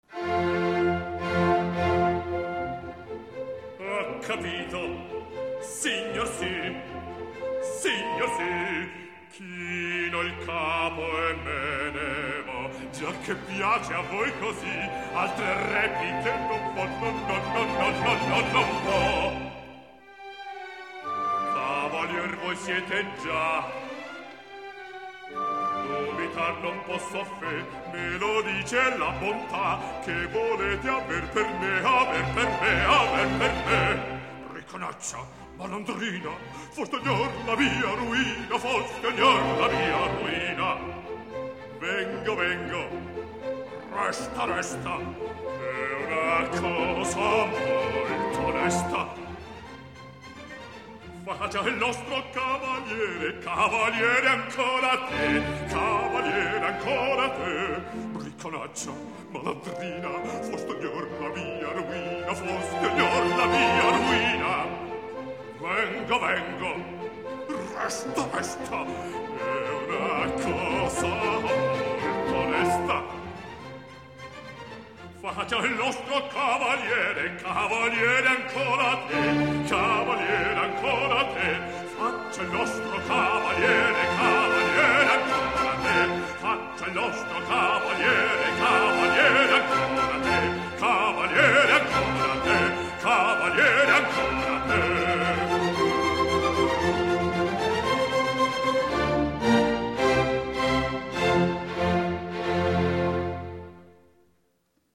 Запись: июнь-июль 1966 года, Лондон.